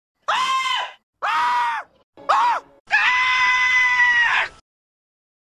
meme
Homer Simpson Scream